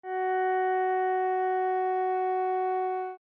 Gb4.mp3